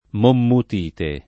monmouthite [ mommut & te ]